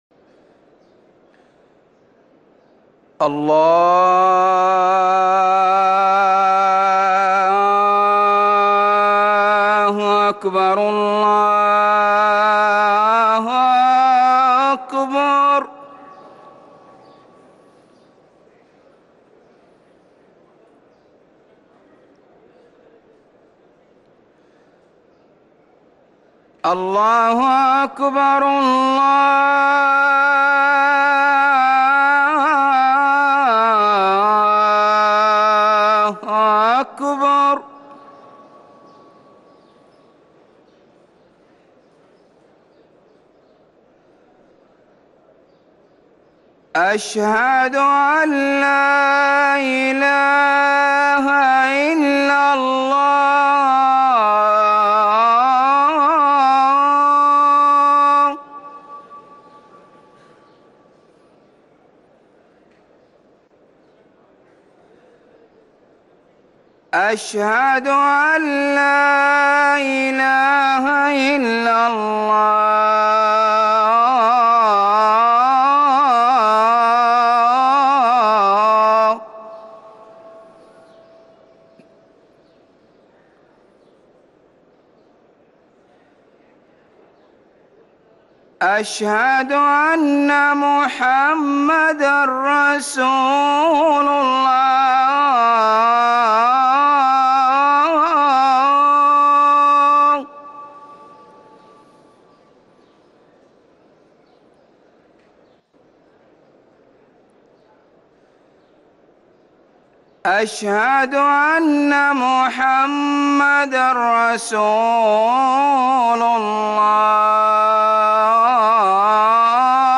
اذان الظهر